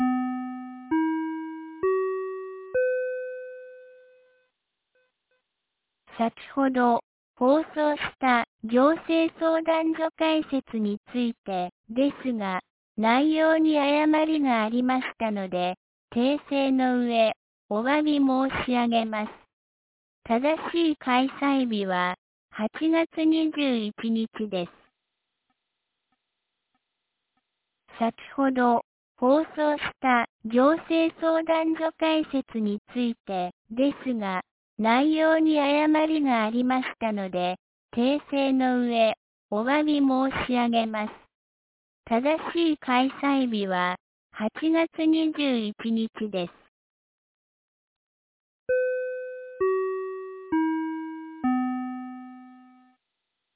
2025年08月13日 18時26分に、由良町から全地区へ放送がありました。